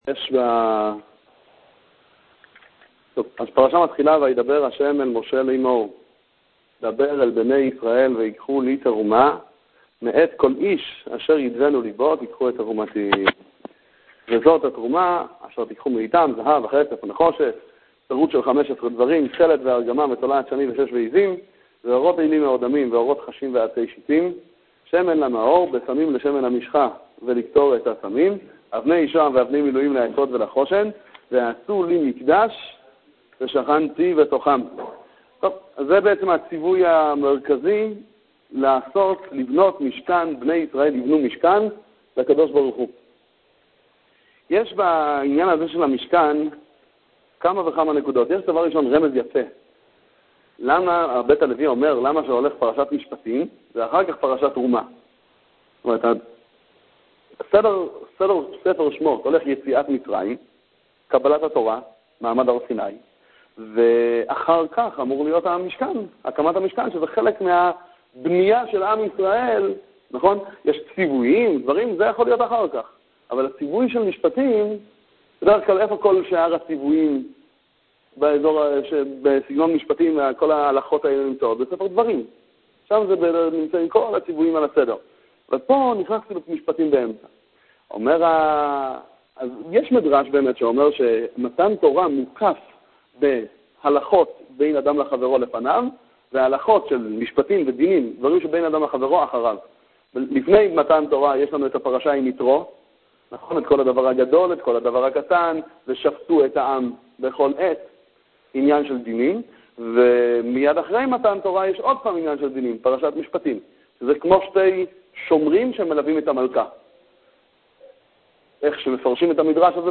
שיעורי תורה לפרשת תרומה